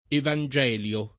evangelio [ evan J$ l L o ]